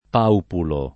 paupulo [ p # upulo ] — latinismo lett., del verso che fanno i pavoni